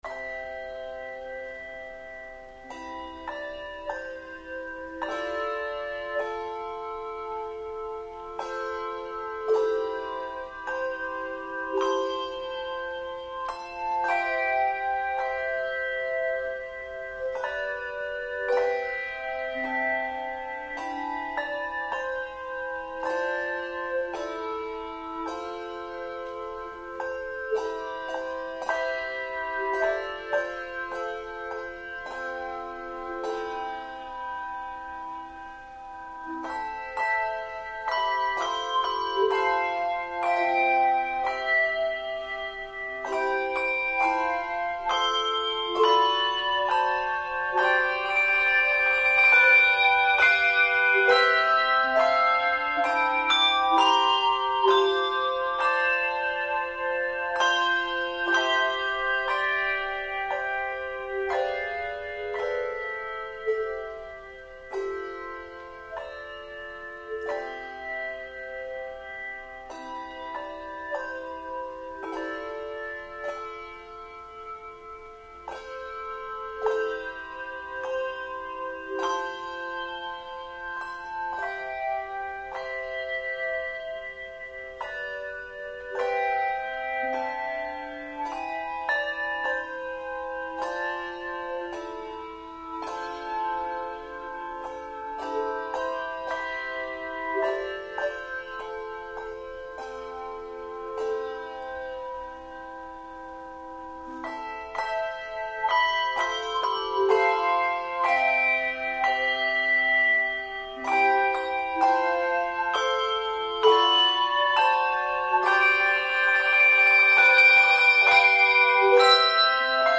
Handbell Quartet
Genre Sacred